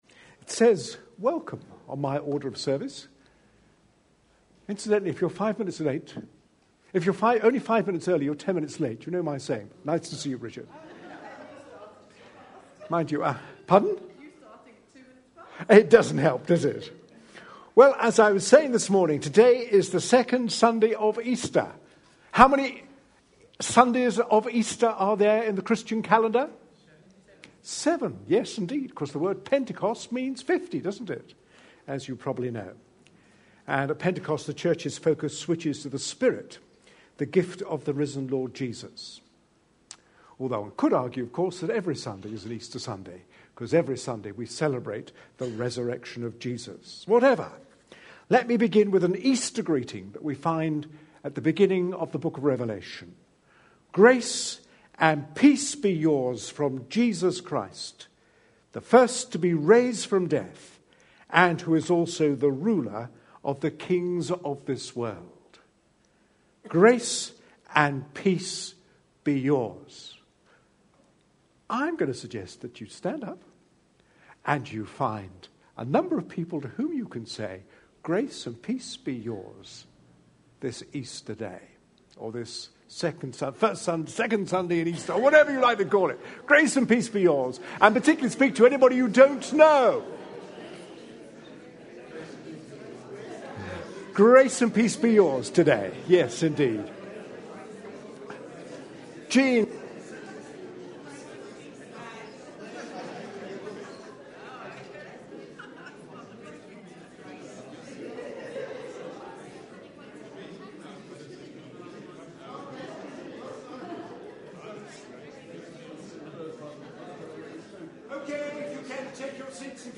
A sermon preached on 7th April, 2013.